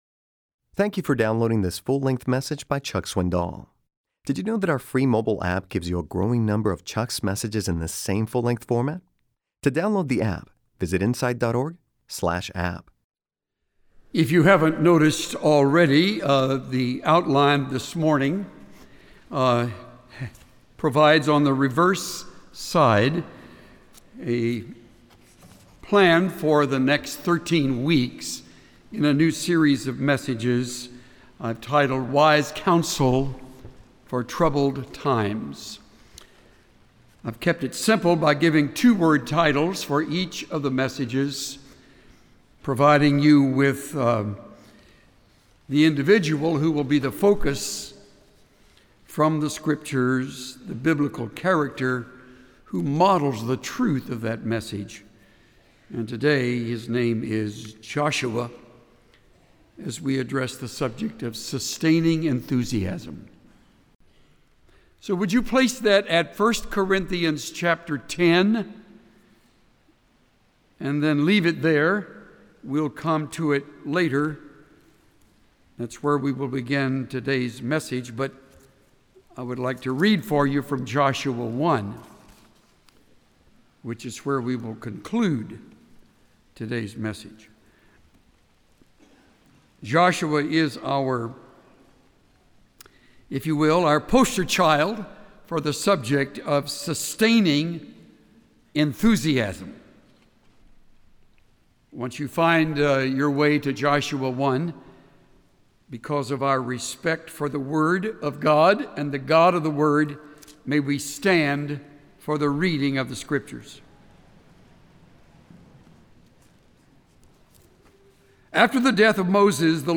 Pastor Chuck Swindoll teaches us not to fear the dark storm but to trust God’s grace.